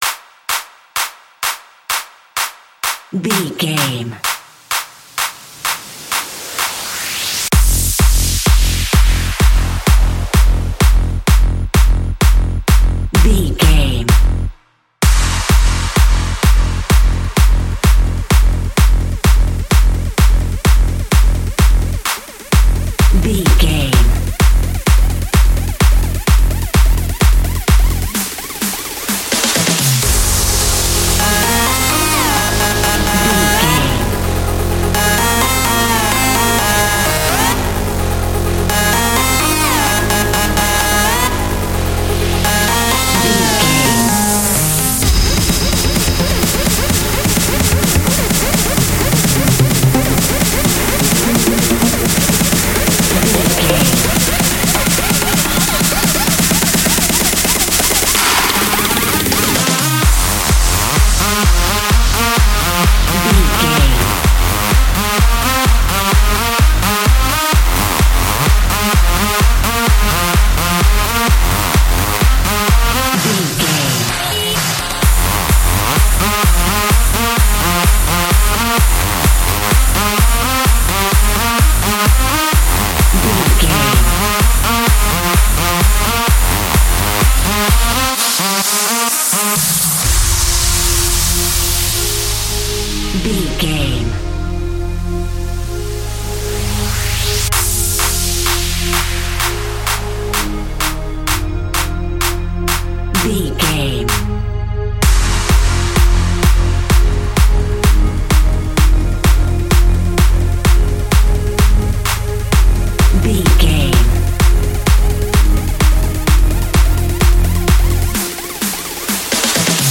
Aeolian/Minor
hard
driving
intense
powerful
energetic
futuristic
epic
synthesiser
drum machine
acid house
uptempo
synth leads
synth bass